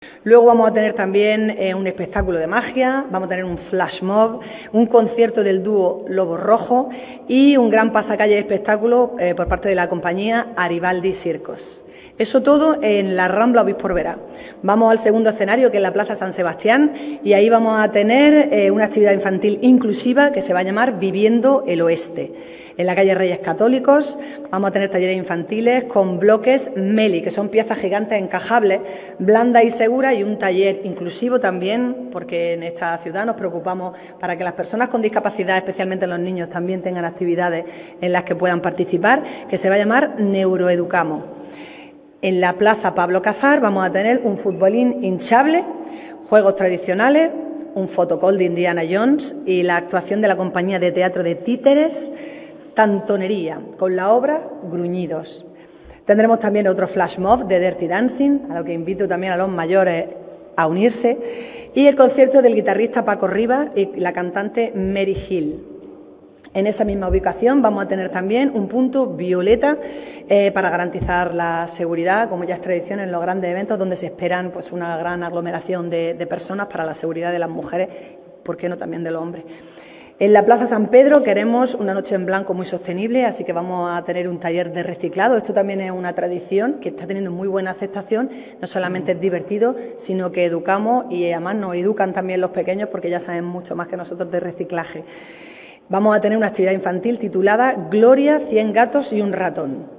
La presentación de este evento se ha llevado a cabo en la Casa Consistorial y la alcaldesa ha estado acompañada por la concejala de Empleo, Comercio, Juventud y Emprendimiento, Lorena Nieto, por el delegado de Empleo de la Junta de Andalucía, Amós García, y  por la diputada provincial de Igualdad y Familia, María Luisa Cruz.